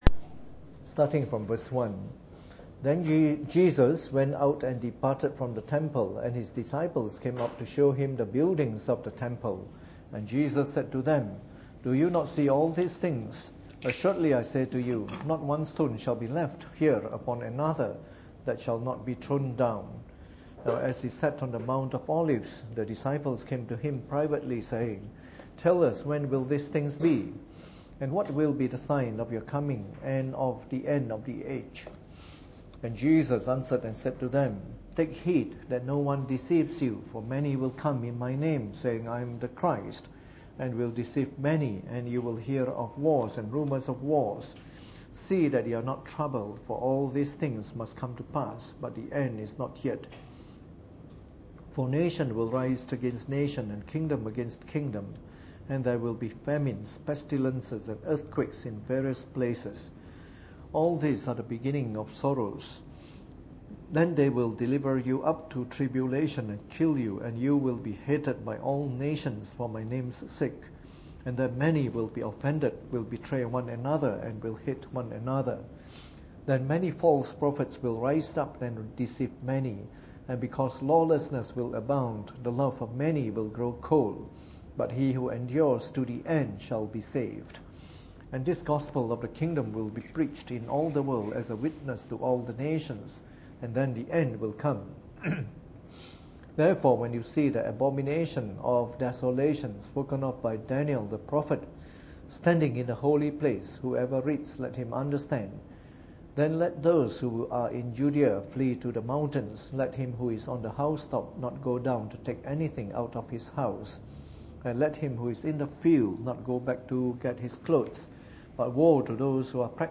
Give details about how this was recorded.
Preached on the 27th of August 2014 during the Bible Study, from our new series of talks on Eschatology.